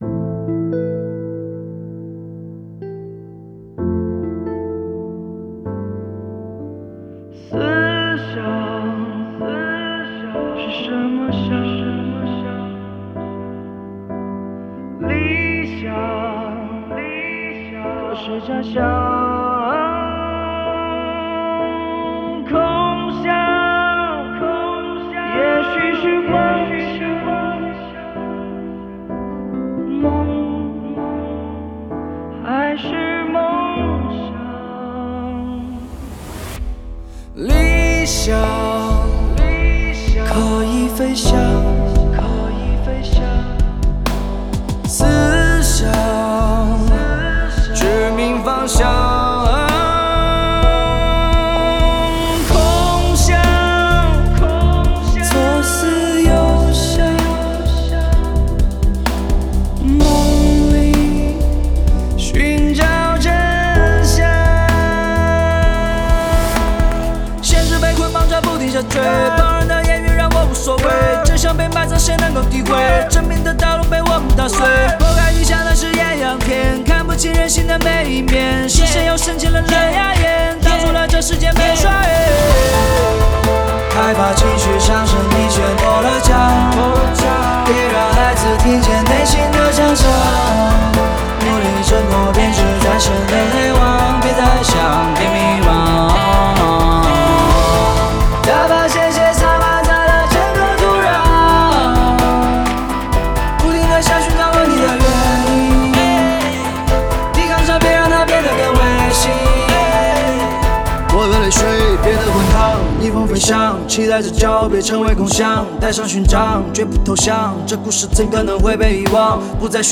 Ps：在线试听为压缩音质节选，